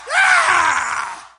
Howard Dean Scream